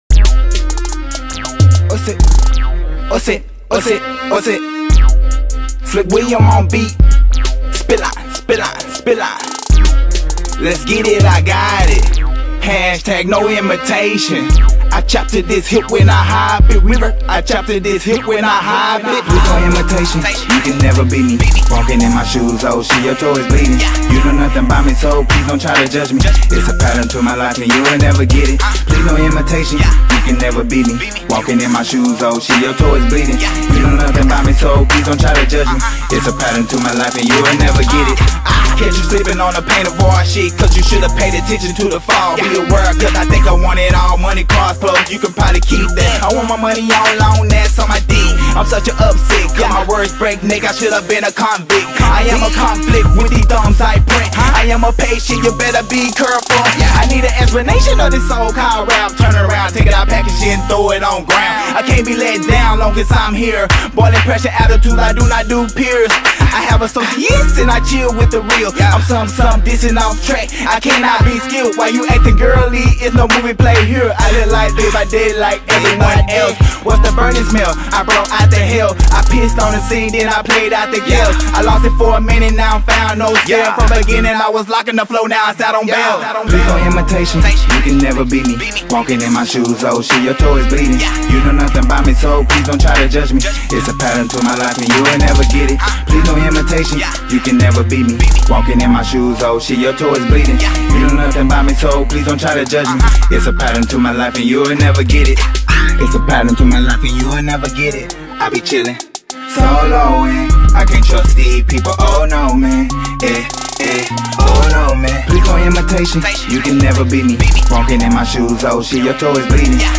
I was writing Raps.